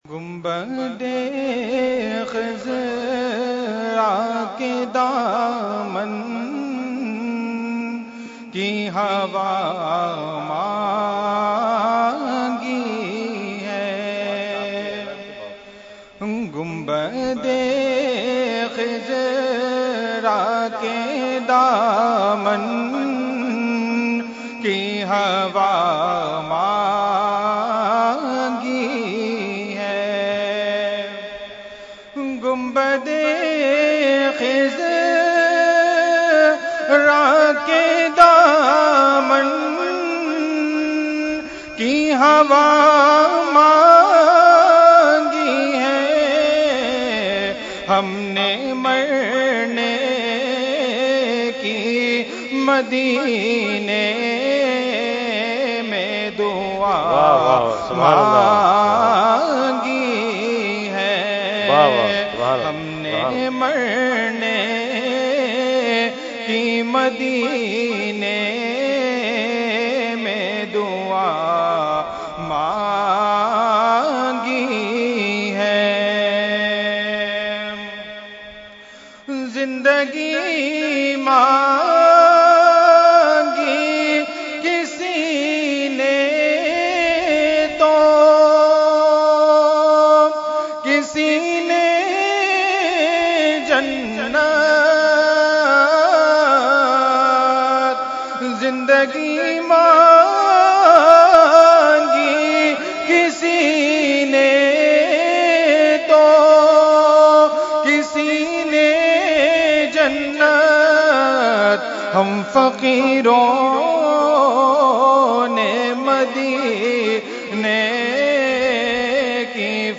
Category : Naat | Language : UrduEvent : Urs Ashraful Mashaikh 2017